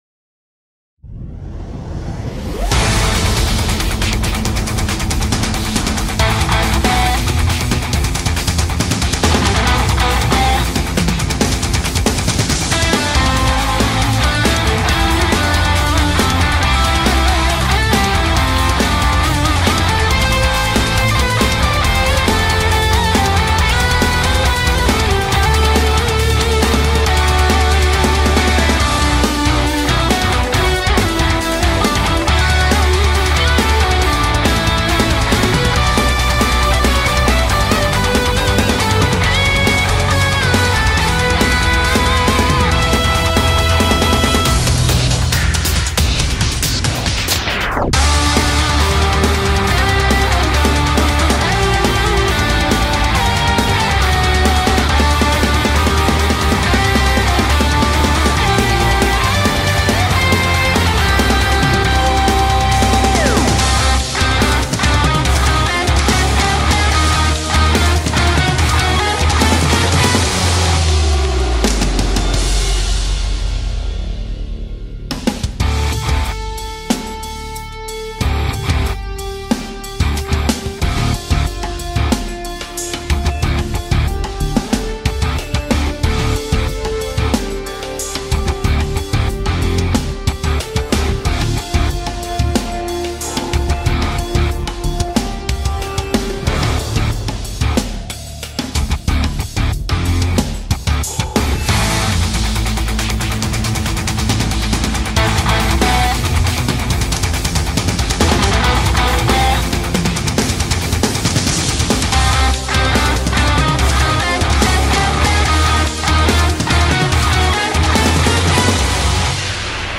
Metal Guitar Cover/Remix